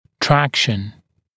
[‘trækʃn][‘трэкшн]тяга, вытяжение (напр. дистопированного клыка)